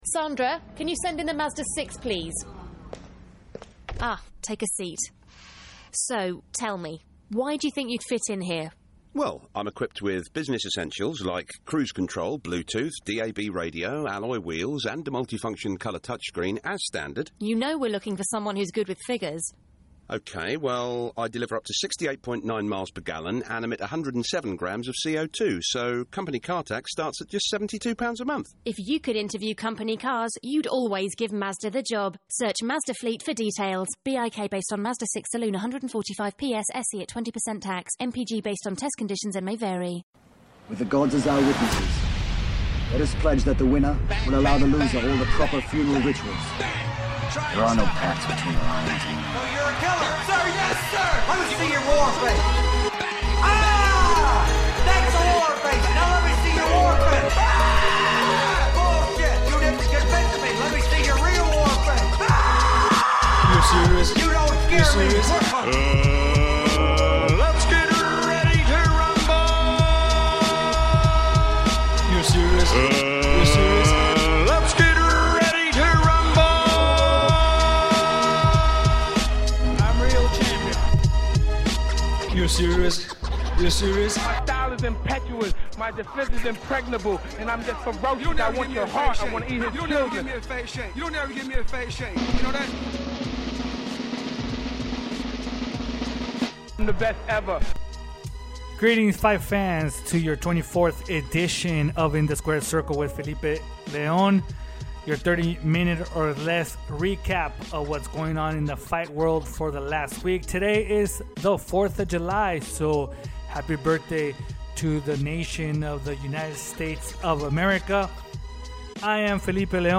a fast pace style of 30 minutes or less.